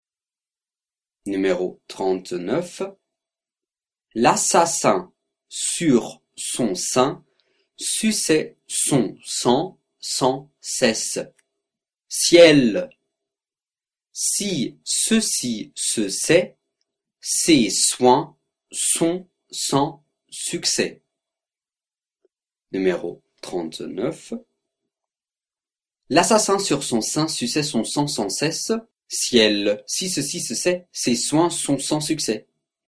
39 Virelangue